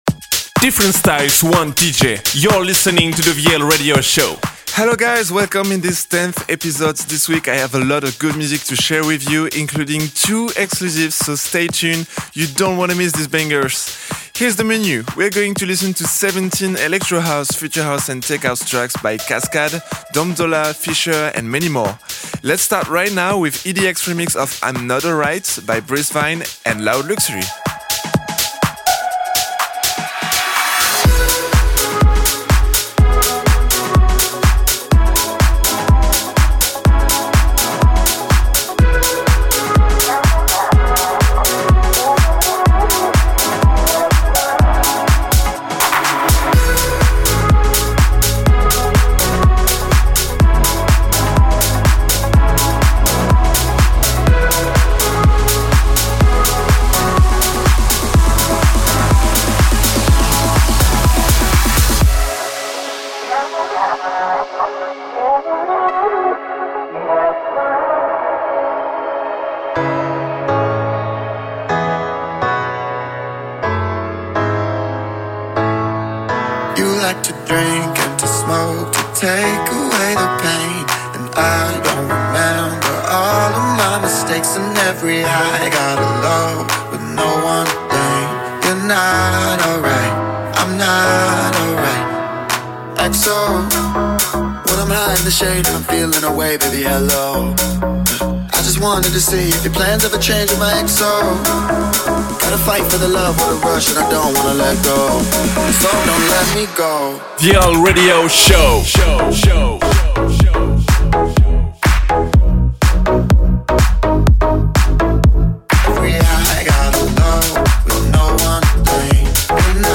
Electro house, future house & tech house DJ mix.